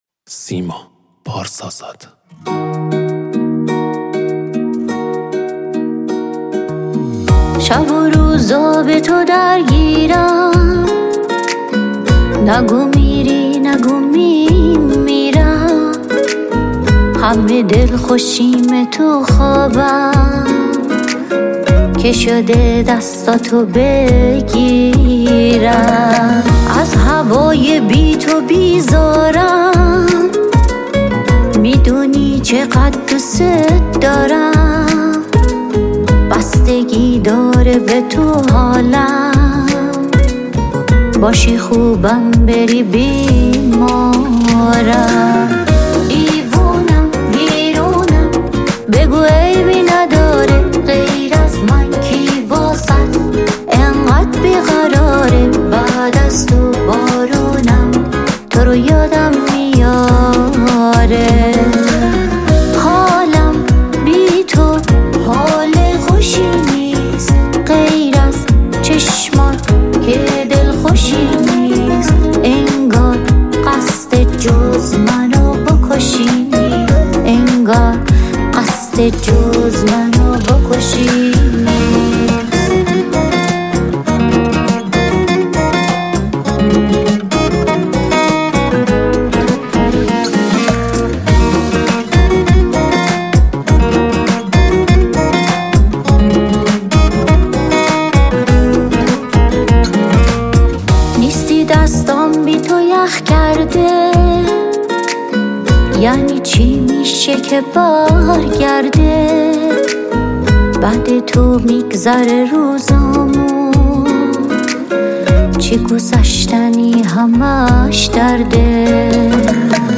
ترانه سرا و خواننده ایرانی
موزیک ویدئو پاپ